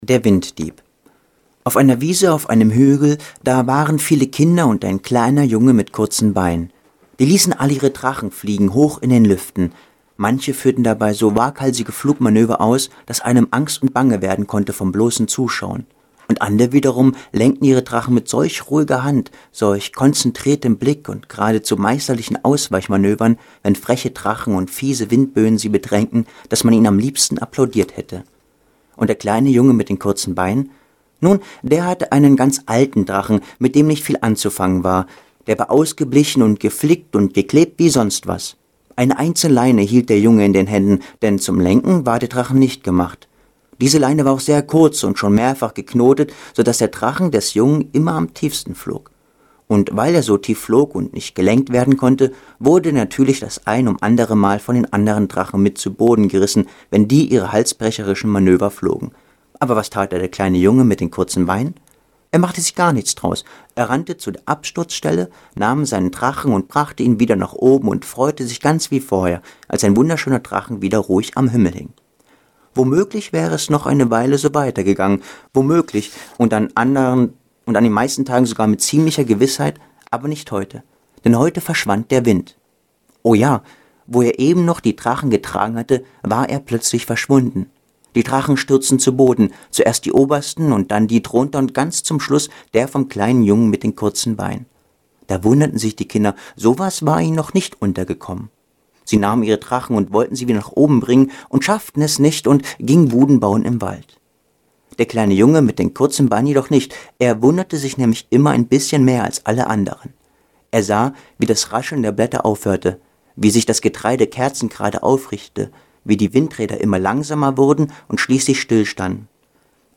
Außerdem las er einen Auszug aus seinem Schaffen vor.